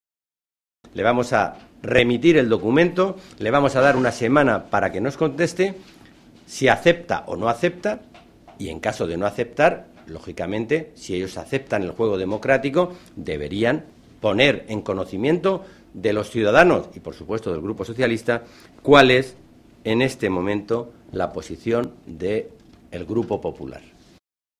Cortes de audio de la rueda de prensa
Audio Alonso r. prensa Congreso